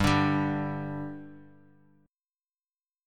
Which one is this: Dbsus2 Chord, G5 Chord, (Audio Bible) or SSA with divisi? G5 Chord